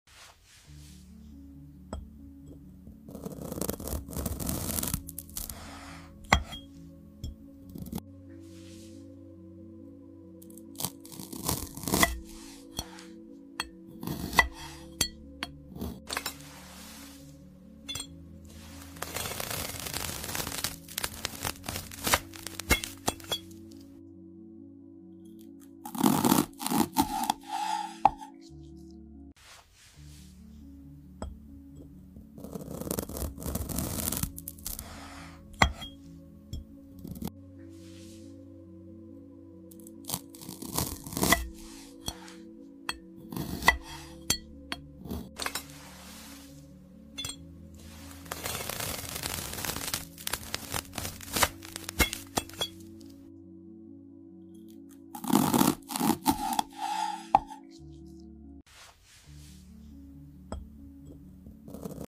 Smooth slices, sizzling vibes, and zero talking — just pure AI-generated ASMR to satisfy your senses 🔪🥓🎧